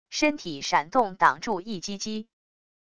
身体闪动挡住一击击wav音频